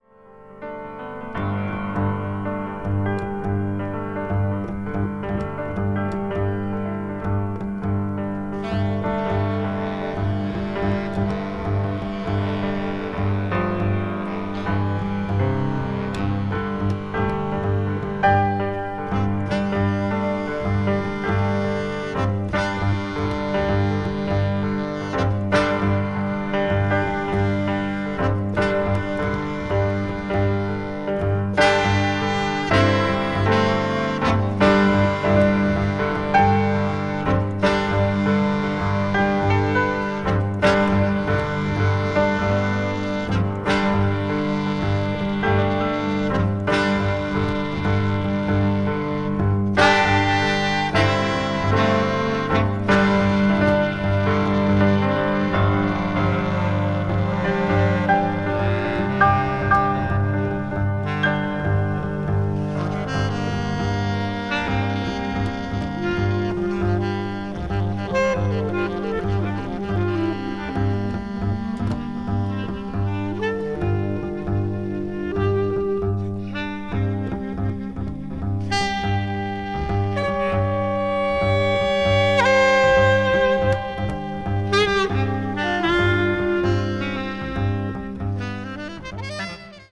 meda : EX/EX(some slightly noises.)
tenor saxophone and bass clarinet
alto saxophone
bass and cello
contemporary jazz   free jazz   post bop   spritual jazz